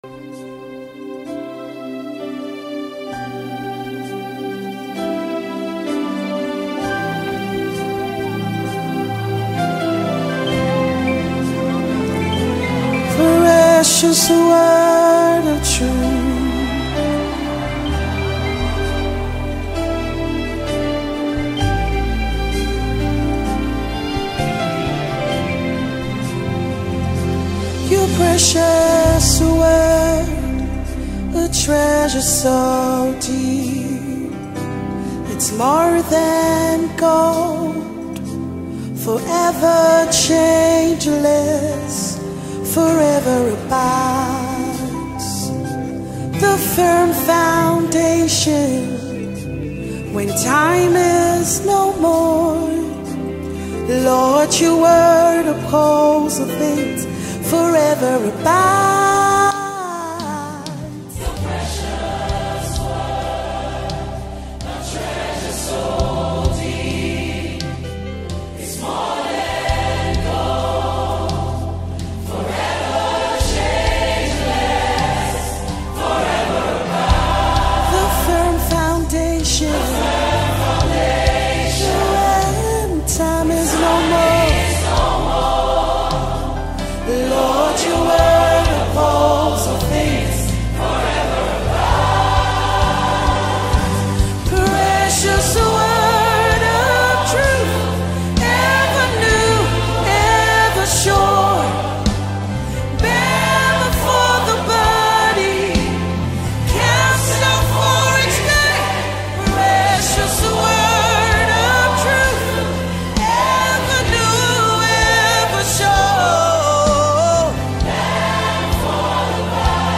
February 10, 2025 Publisher 01 Gospel 0